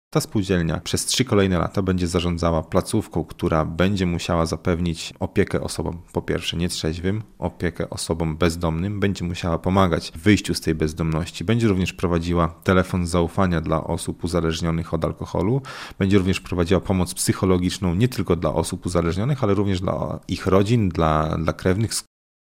Nowe miejsca pracy w Suwałkach - relacja